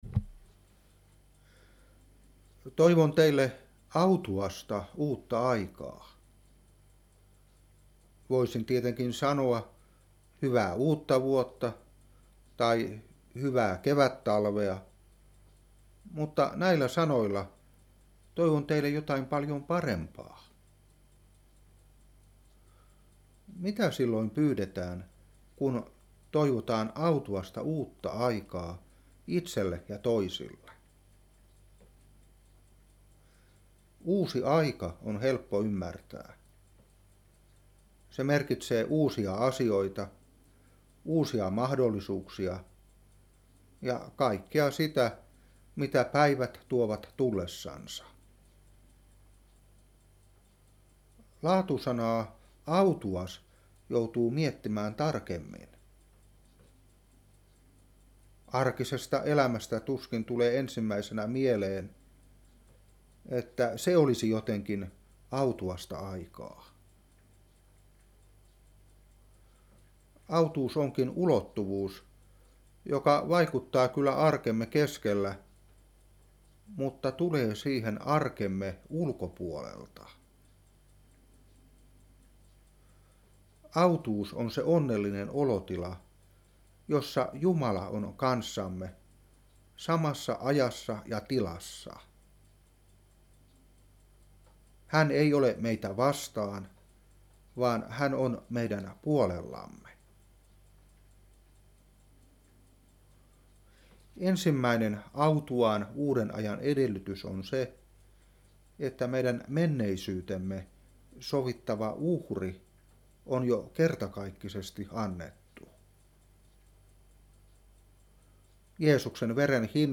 Opetuspuhe 2013-1. Hepr.9:28. Ef.1:7. 2.Piet.1:2-3. Ef.2:8. Room.10:17.